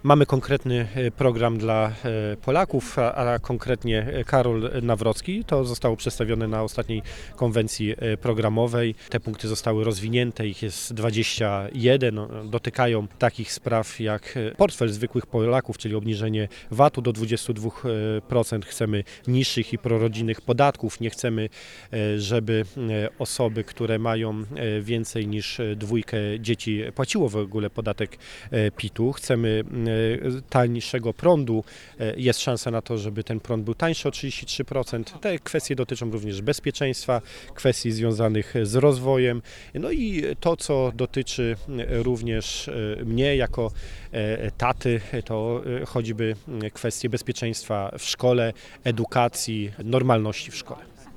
Program kandydata obywatelskiego składa się z 21 punktów, które przybliża Paweł Hreniak.